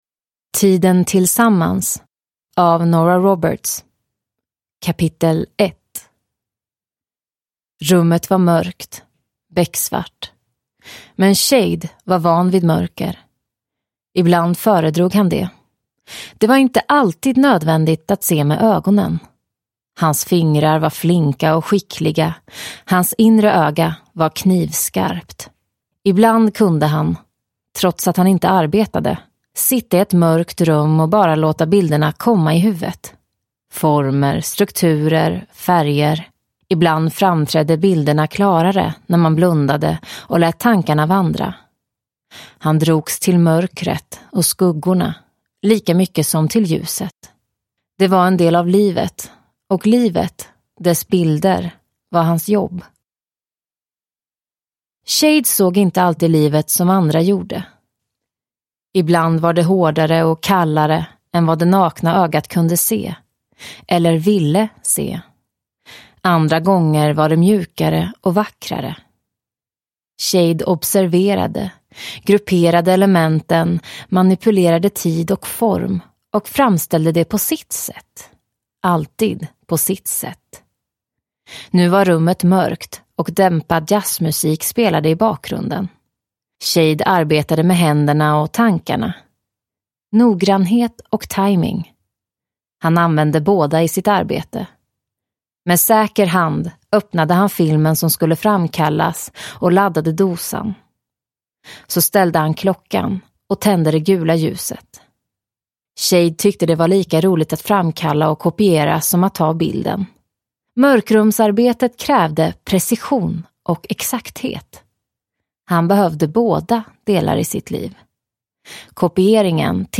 Tiden tillsammans – Ljudbok – Laddas ner